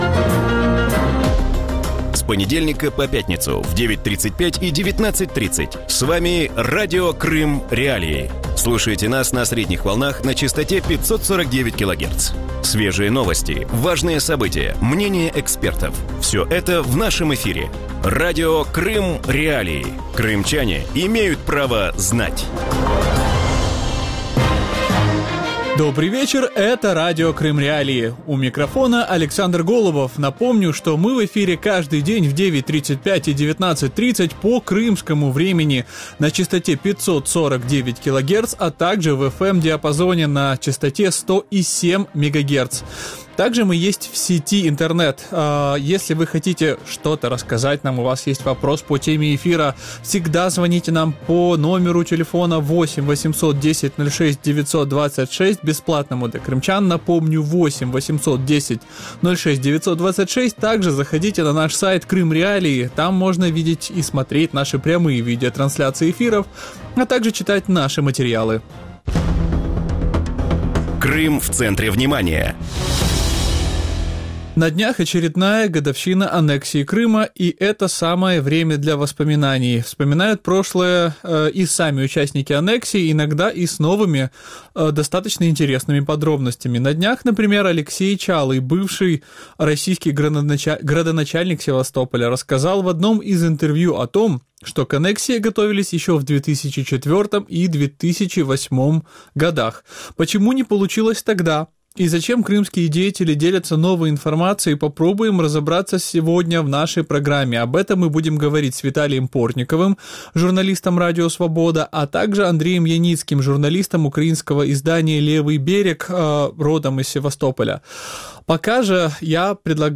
В вечернем эфире Радио Крым.Реалии говорят о заявлениях бывшего «народного мэра» Севастополя Алексея Чалого о том, что к аннексии Крыма начали готовиться еще в 2004 году. Кто и как готовил переворот в Крыму, почему прошлые попытки не увенчались успехом и почему о них говорят подконтрольные Кремлю крымские власти?